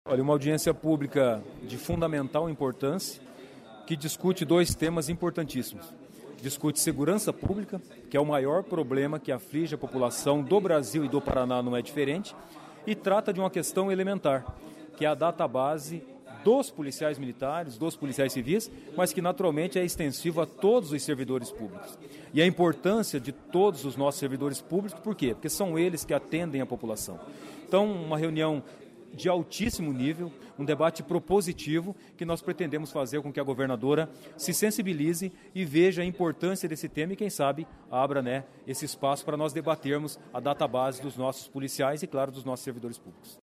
Confira a entrevista com o deputado.